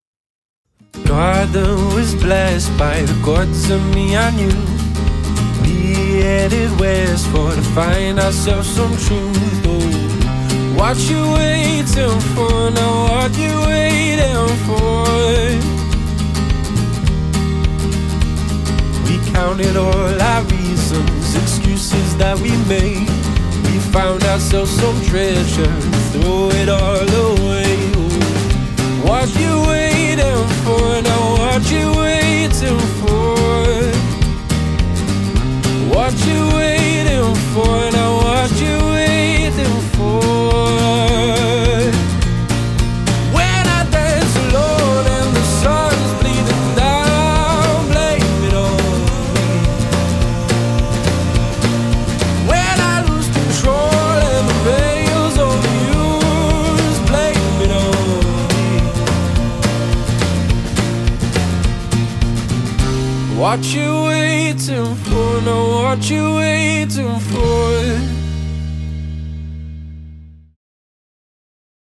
• 3-piece
Vocals/Guitar, Bass/Backing Vocals, Drums/Backing Vocals